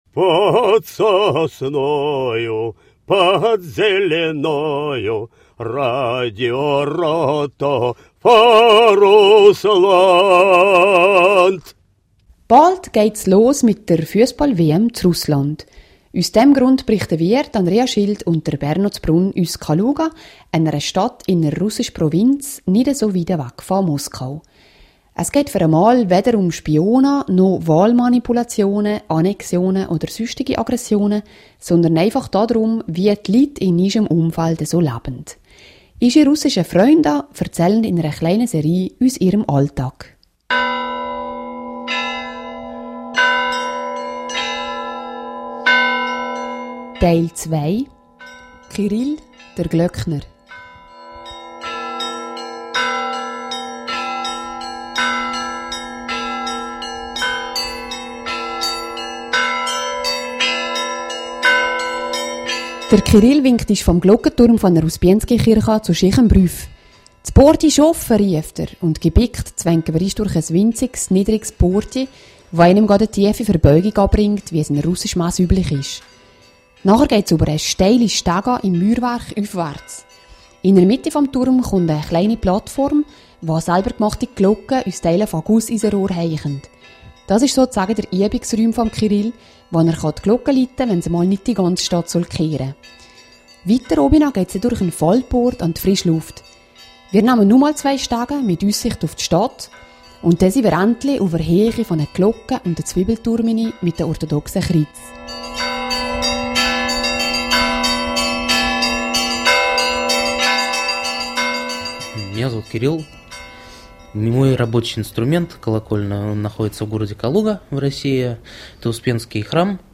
Das Glockenspiel erlebt derzeit eine Renaissance.